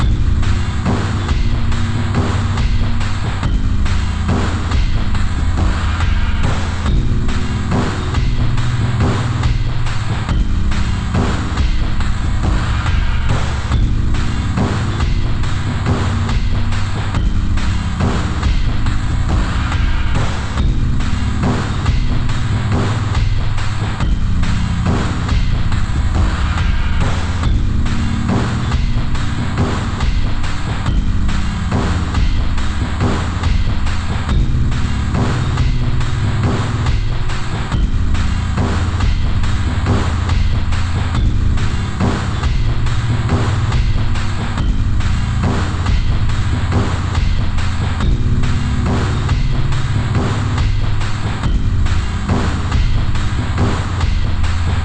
Type BGM
Speed 50%